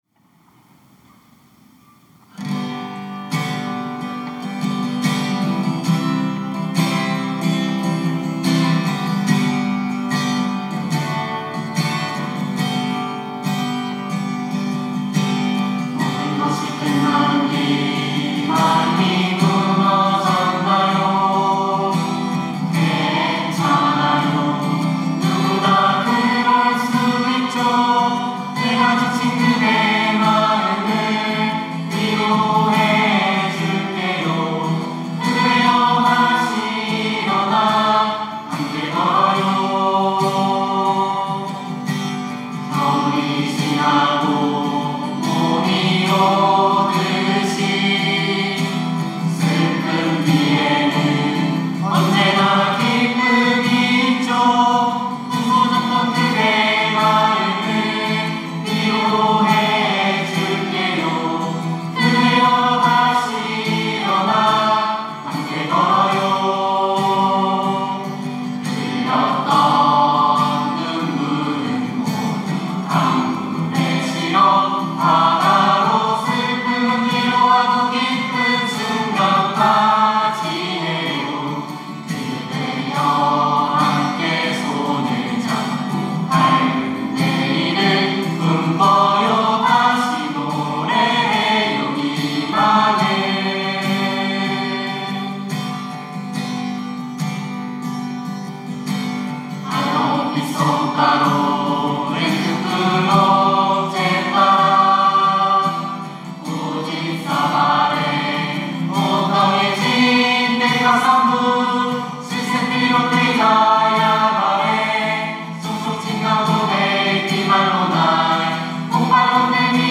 특송과 특주 - 친구
청년부 미얀마 단기선교팀